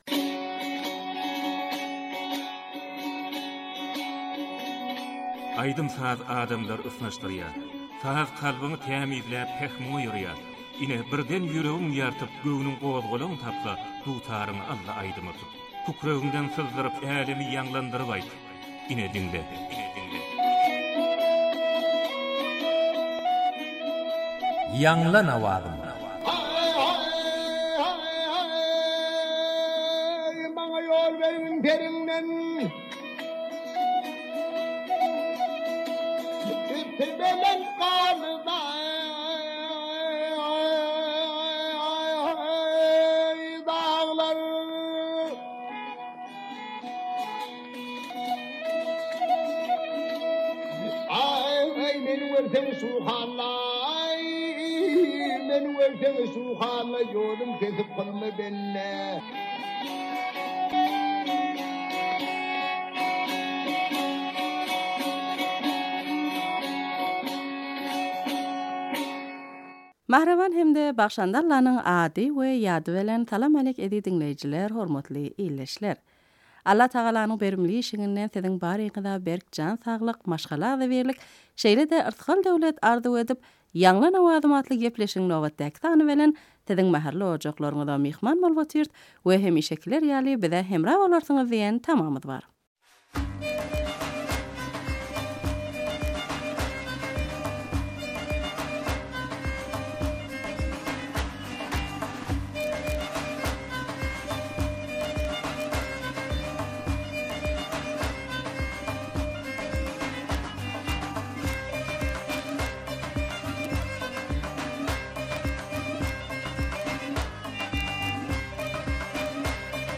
turkmen owaz aýdym şygyrlar